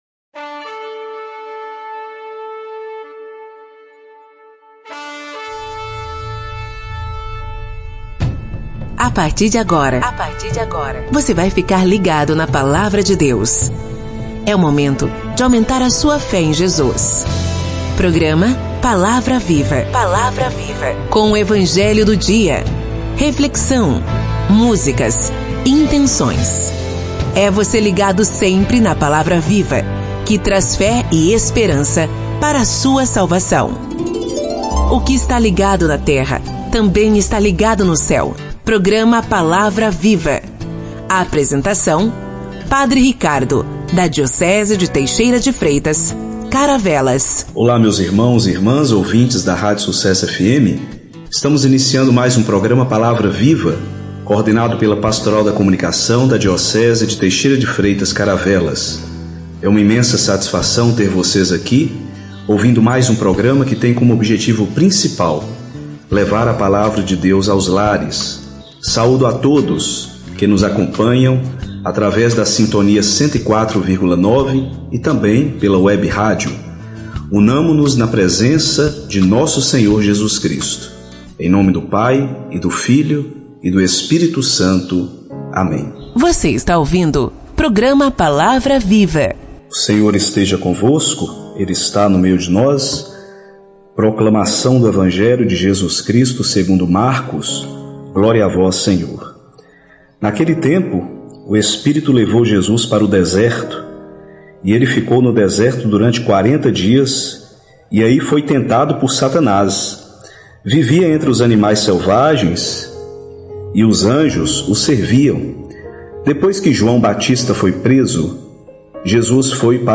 que conta com leitura do evangelho do dia, reflexão, intenções e pedidos de oração de fiéis, músicas, e acontecimentos semanais na Diocese.